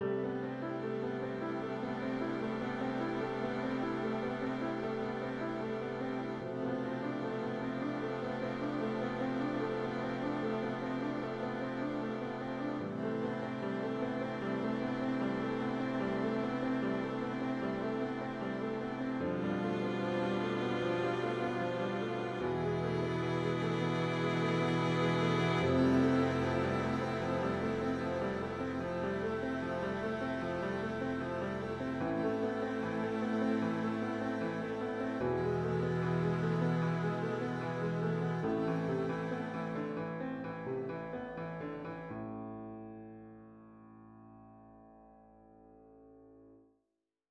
music soundtrack